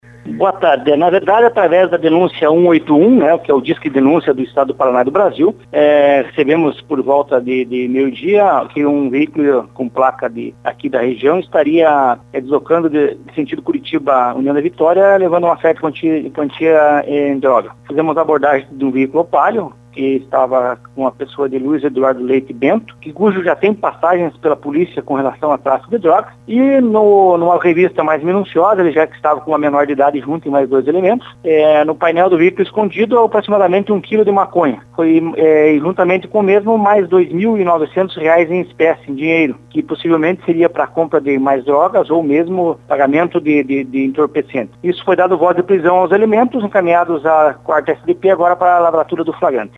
esteve conversando com o jornalismo da Rádio Colmeia, e comenta sobre o trabalho realizado.